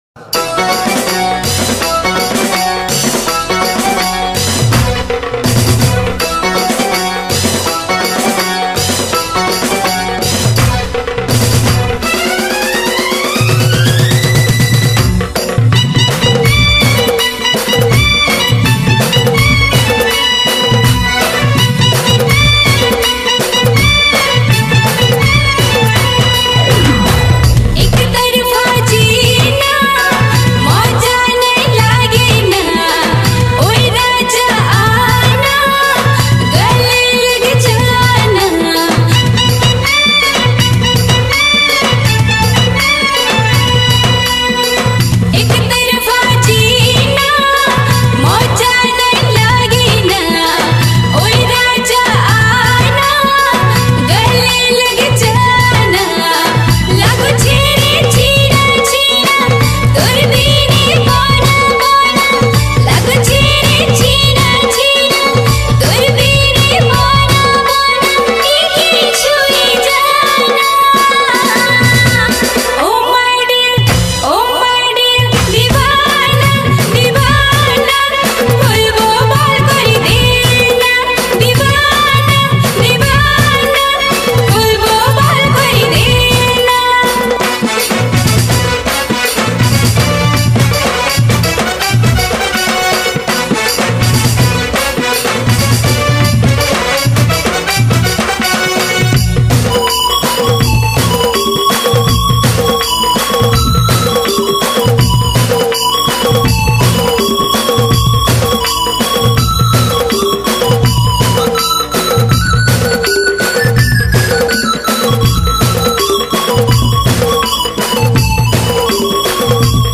Category: New Sambalpuri Folk Songs 2022